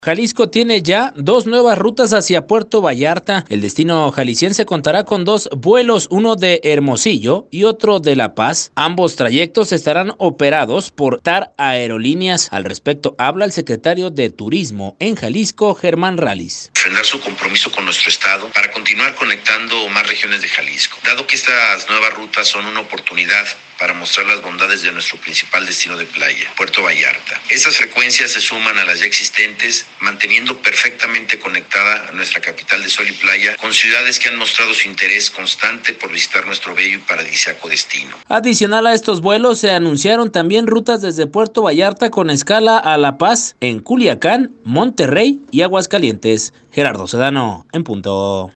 Al respecto habla, el Secretario de Turismo en Jalisco, Germán Ralis: